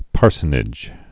(pärsə-nĭj)